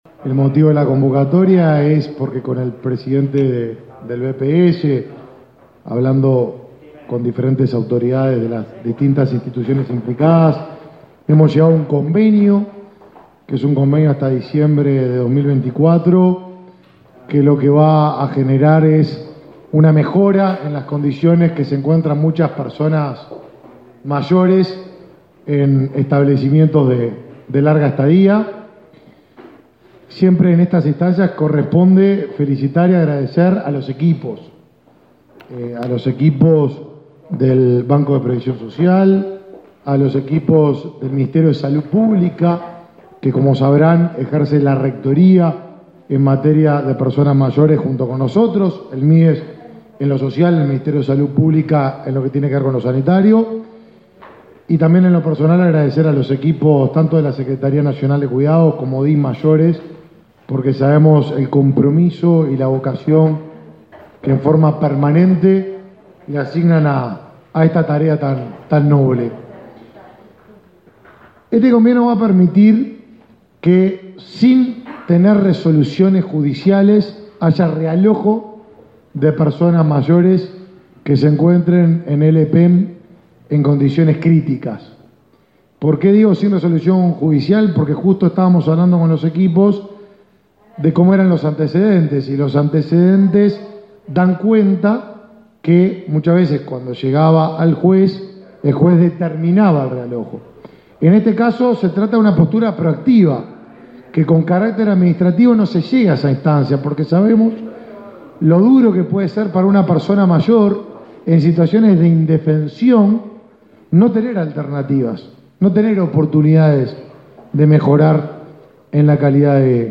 Palabras del ministro de Desarrollo Social y del presidente del BPS